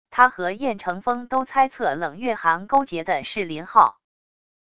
他和燕乘风都猜测冷月寒勾结的是林昊wav音频生成系统WAV Audio Player